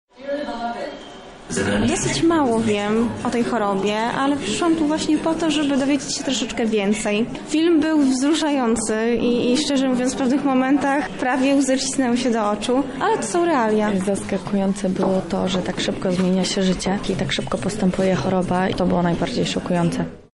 O swoich wrażeniach mówią widzowie, którzy obejrzeli projekcję w Teatrze Starym: